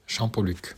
Champoluc (French: [ʃɑ̃pɔlyk]